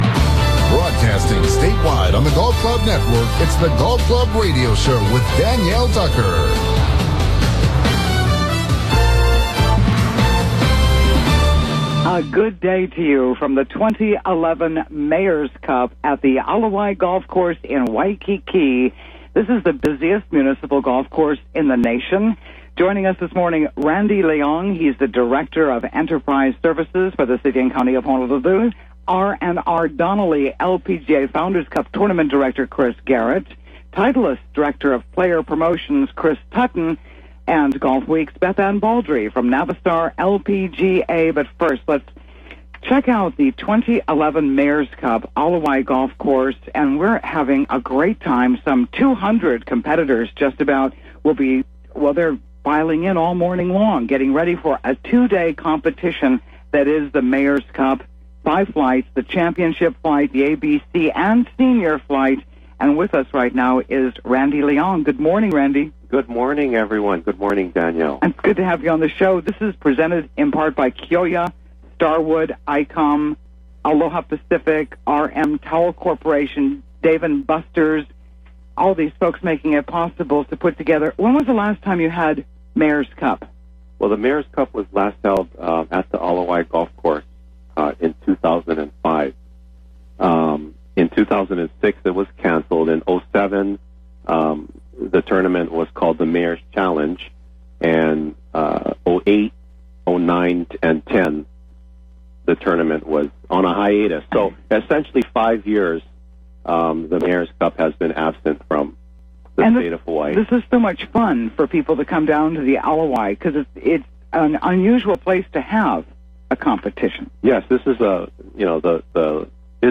In the Clubhouse: Live Remote from The Ala Wai �Mayor�s Cup�